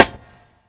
UH_RIM.wav